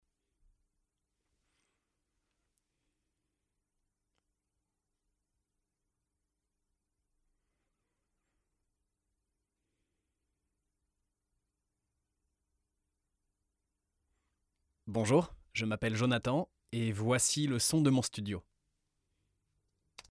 Male
20s, 30s
Corporate, Friendly, Natural, Young
Commercial, Animation, IVR or Phone Messaging, Video Game, E-Learning, Explainer
Microphone: TLM 103
Audio equipment: Golden Age PRE-73 / Solid State Logic 2+